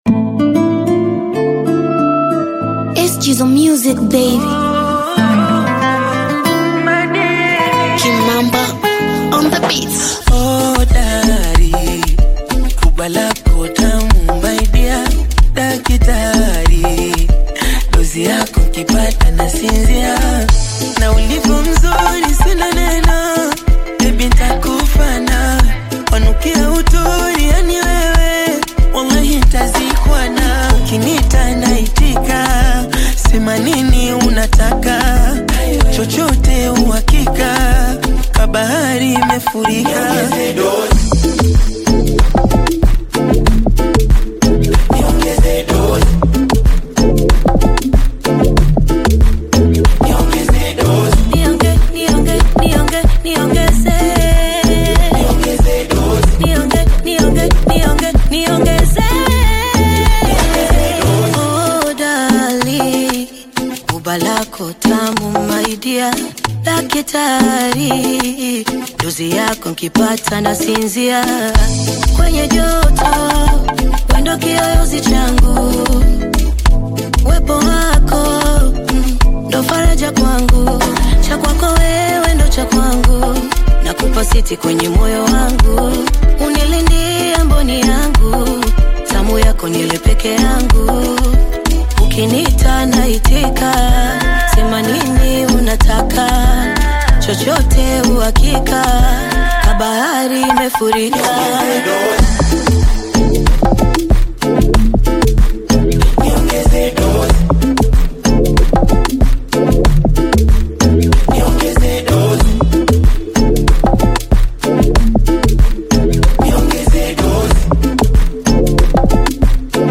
Bongo Flava
love song
creating a captivating and romantic atmosphere.